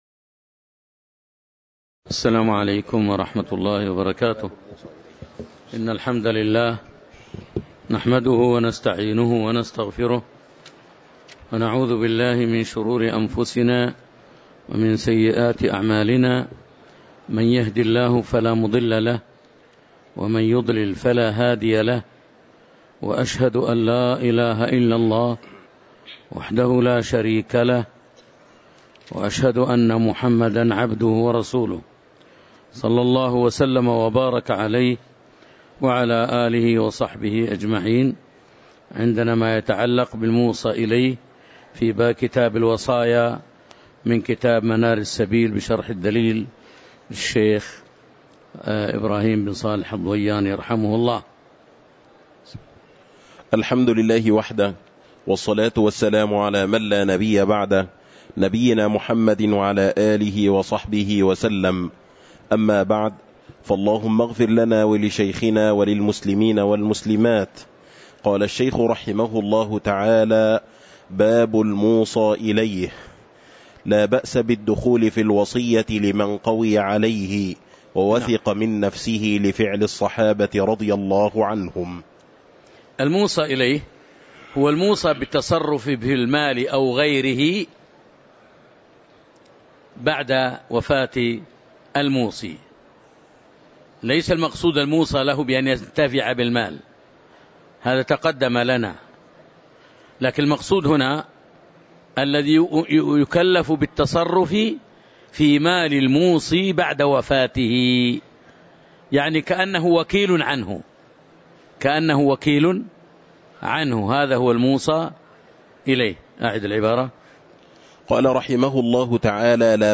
تاريخ النشر ٧ ربيع الأول ١٤٤٦ هـ المكان: المسجد النبوي الشيخ